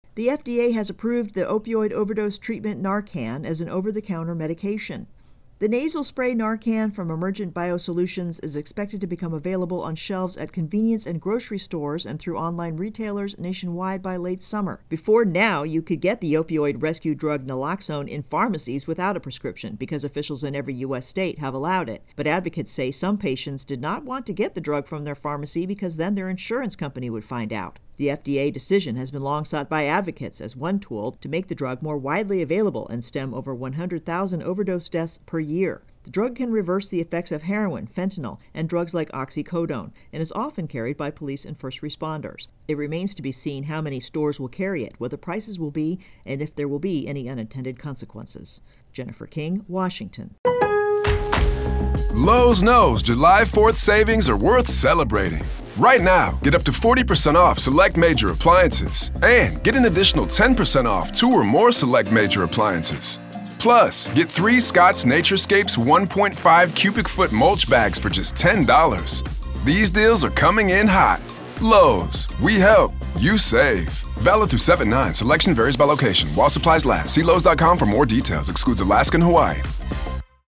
reports on Opioid Crisis Naloxone-FDA approval.